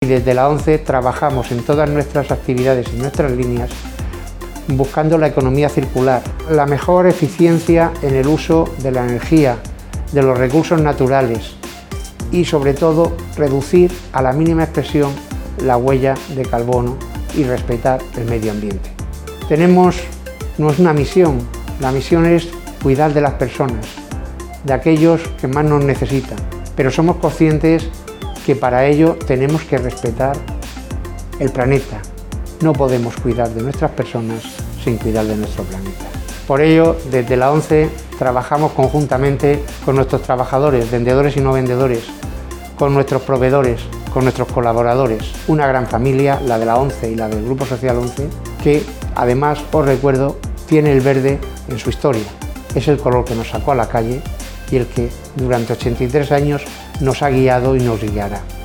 en el acto de inauguración del evento